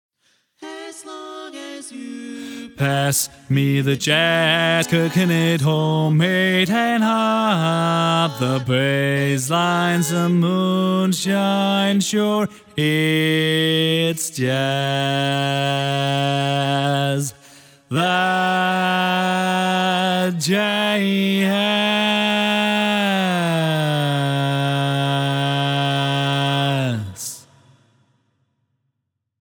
Key written in: D♭ Major
Type: Barbershop